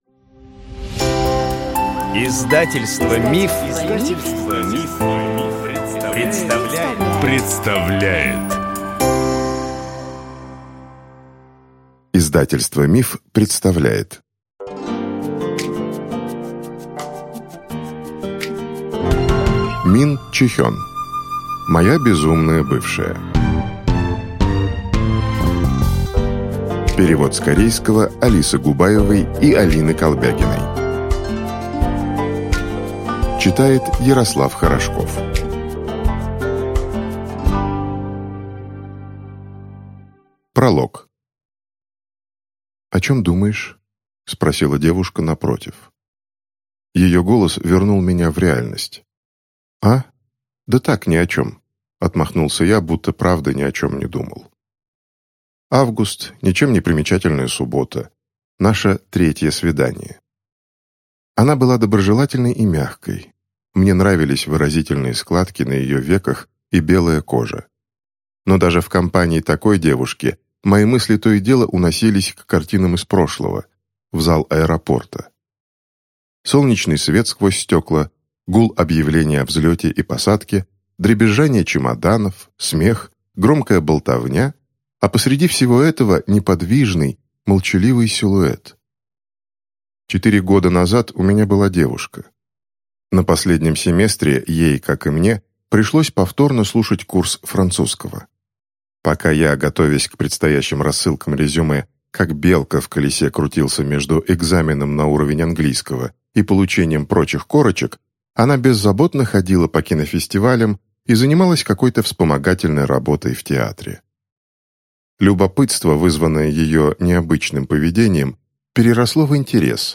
Аудиокнига Моя безумная бывшая | Библиотека аудиокниг